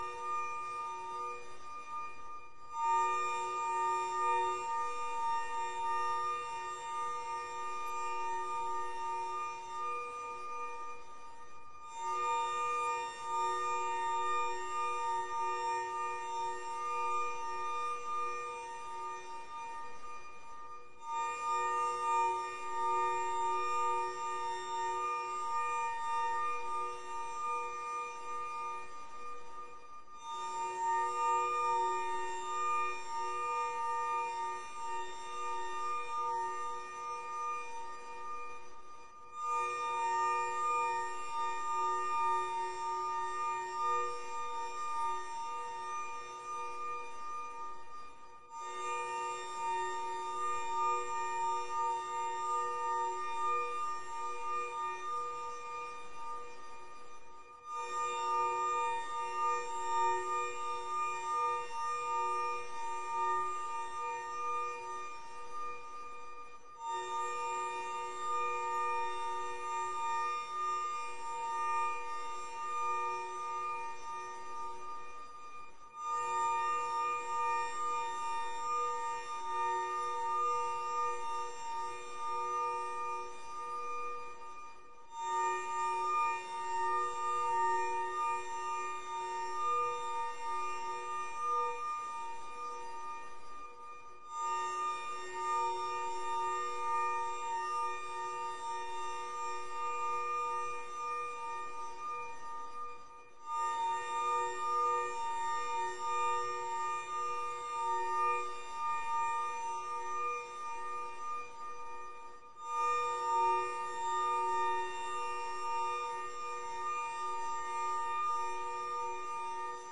口琴无人机 " harm2
描述：用口琴创作的无人机。
Tag: 风能 声学环境 处理 无人驾驶飞机 样品 迷惑 口琴